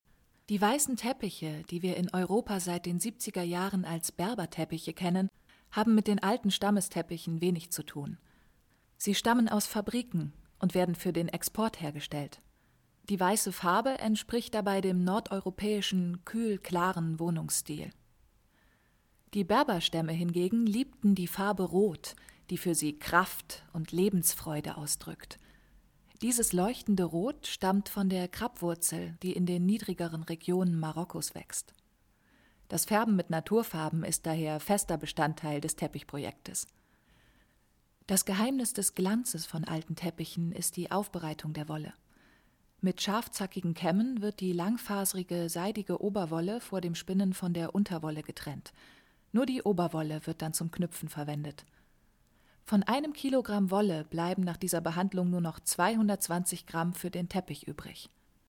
Sprecherin und Schauspielerin. Synchronsprecherin, Studiosprecherin
Sprechprobe: Werbung (Muttersprache):
female german voice over talent